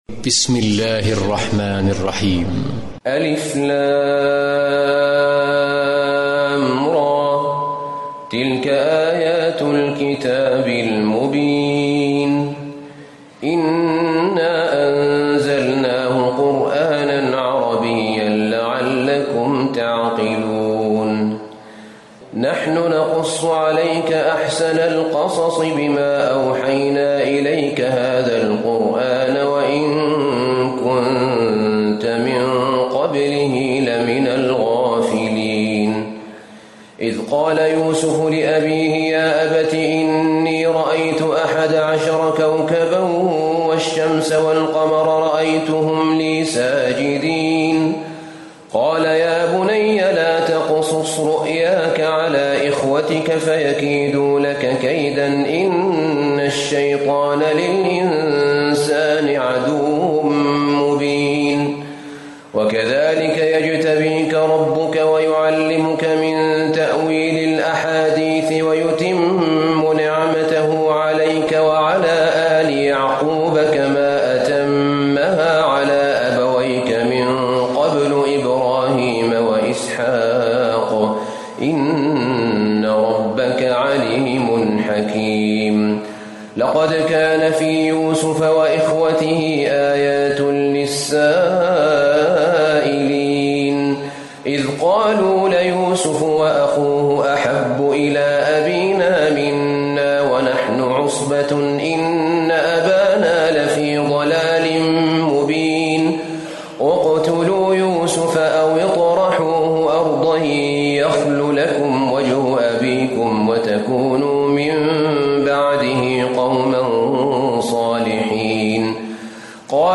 تراويح الليلة الثانية عشر رمضان 1437هـ سورة يوسف (1-111) Taraweeh 12 st night Ramadan 1437H from Surah Yusuf > تراويح الحرم النبوي عام 1437 🕌 > التراويح - تلاوات الحرمين